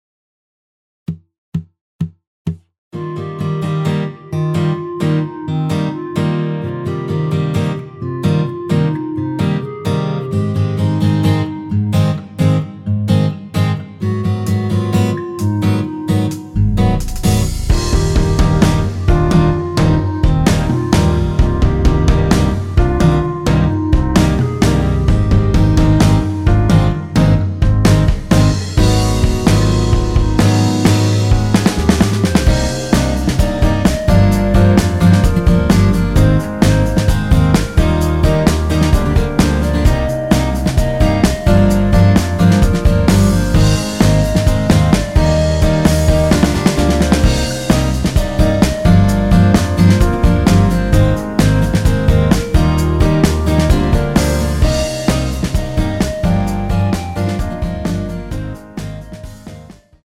전주 없이 시작하는 곡이라 4박 카운트 넣어 놓았습니다.(미리듣기 확인)
원키에서(-2)내린 멜로디 포함된 MR입니다.
Eb
앞부분30초, 뒷부분30초씩 편집해서 올려 드리고 있습니다.
중간에 음이 끈어지고 다시 나오는 이유는